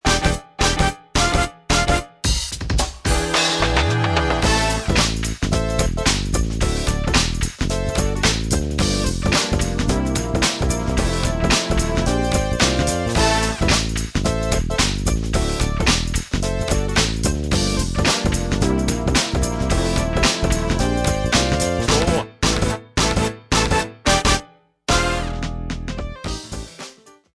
リズムの裏にかすかに歪んだブレークビートを混ぜ込んでみると、
ベースもサビの前に「ドゥウウウン」とスライドを入れる事で
曲がハネているのにフィルだけはハネてないんです。
ラップの声がたまたま紛れ込んでしまいました。